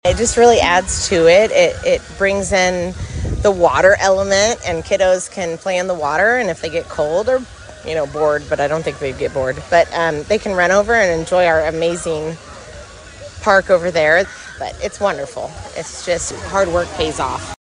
Mayor Lydia Simmons says it’s a big addition to the community-built playground, opened to the public in 2016, and it’s big for the city as a whole.